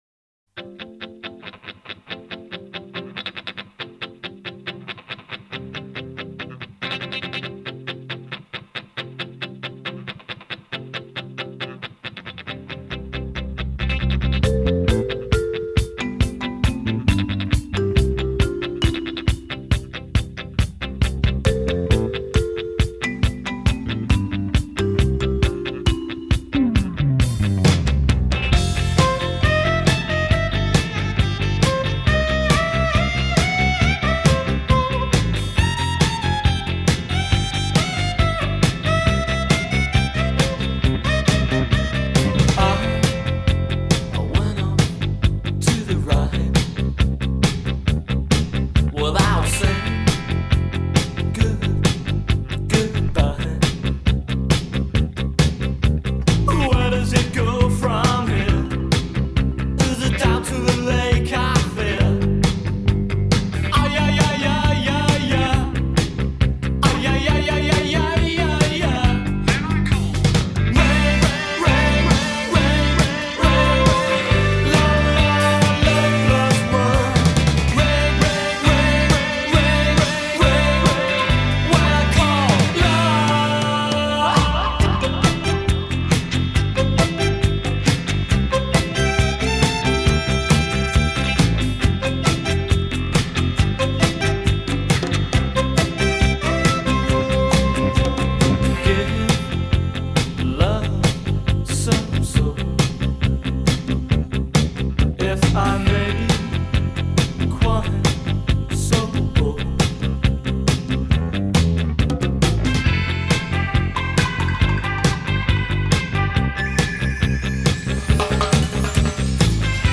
12″ single